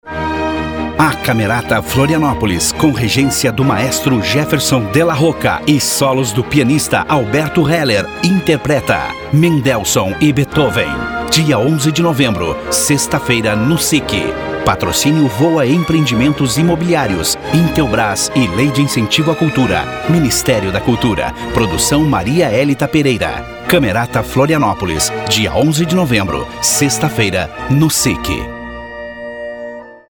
• spot